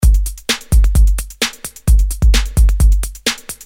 Free MP3 electro drumloops soundbank 2
Electro rythm - 130bpm 26